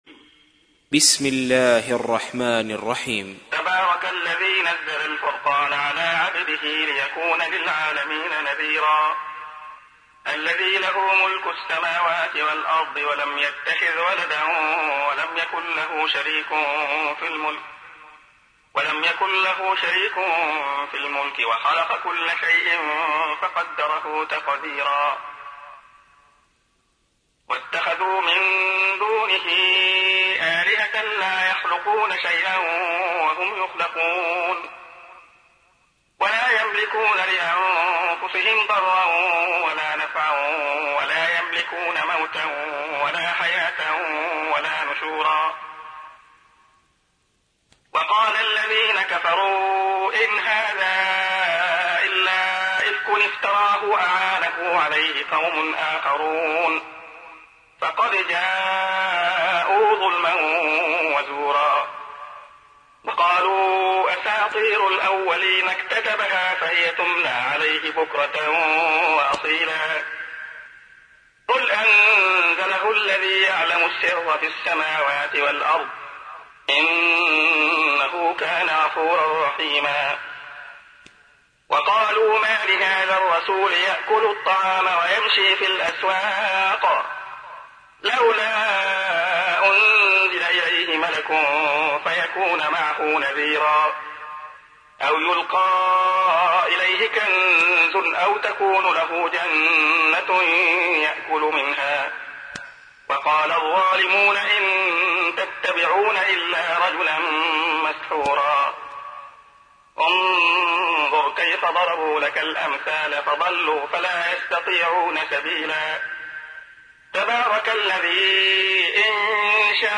تحميل : 25. سورة الفرقان / القارئ عبد الله خياط / القرآن الكريم / موقع يا حسين